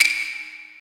soft-hitwhistle.mp3